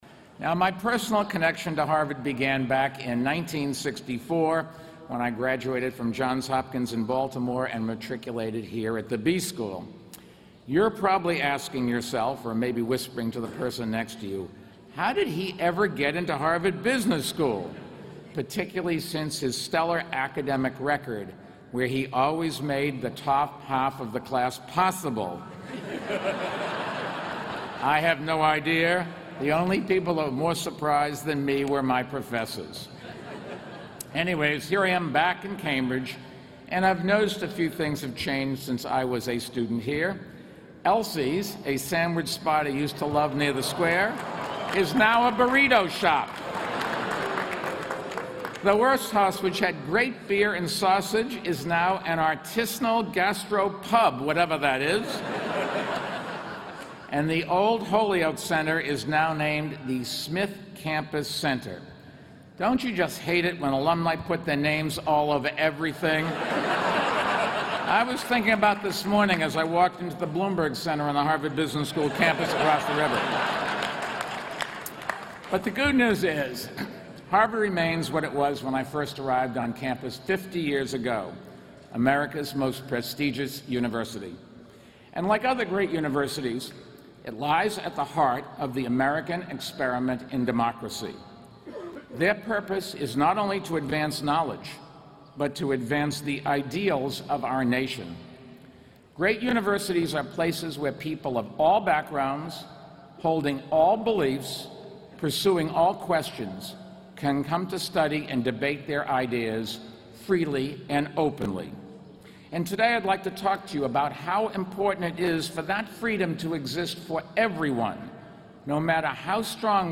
公众人物毕业演讲第10期:迈克尔·彭博哈佛大学2014毕业典礼演讲(2) 听力文件下载—在线英语听力室